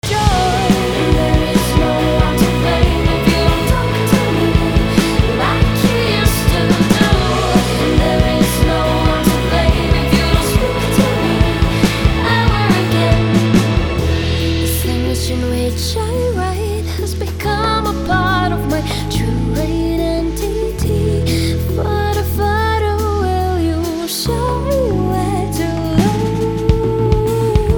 a Macedonian pop rock indie jazz folk supergroup
Lead Vocal
Bass
Saxophone
Drums